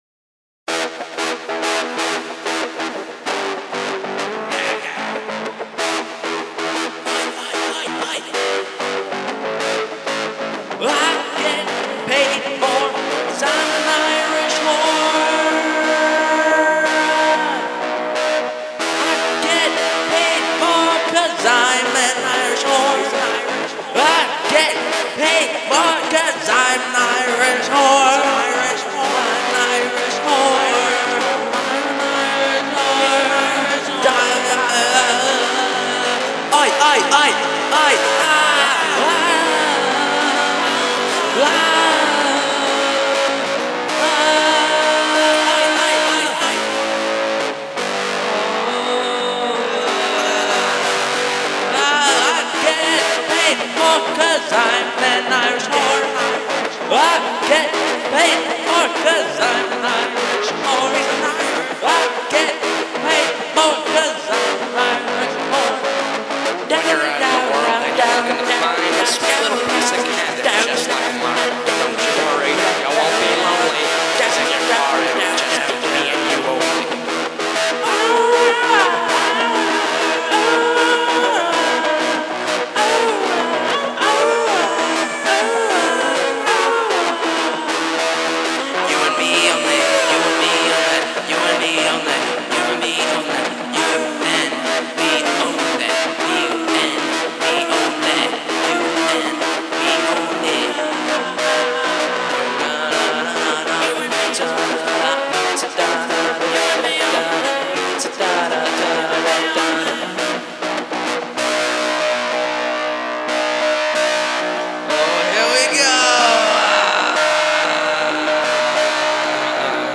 Rock
Funny Funny Song!